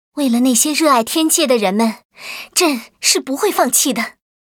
文件 文件历史 文件用途 全域文件用途 Erze_amb_06.ogg （Ogg Vorbis声音文件，长度4.6秒，96 kbps，文件大小：54 KB） 源地址:地下城与勇士游戏语音 文件历史 点击某个日期/时间查看对应时刻的文件。